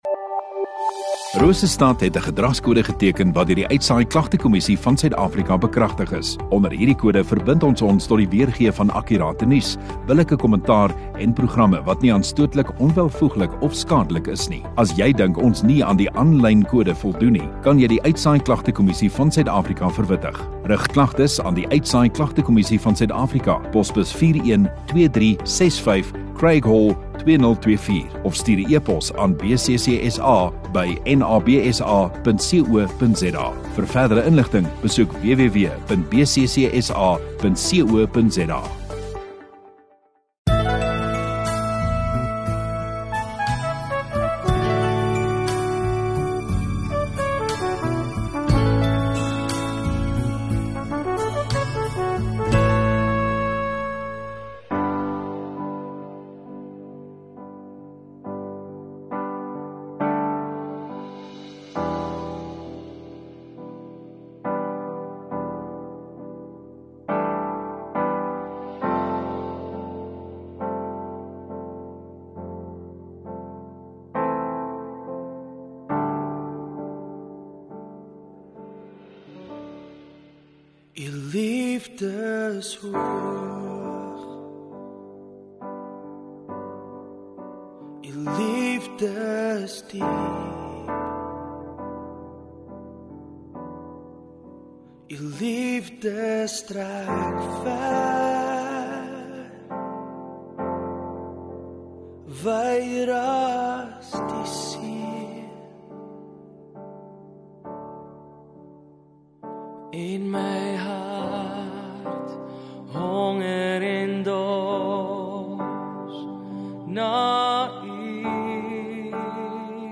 19 May Sondagaand Erediens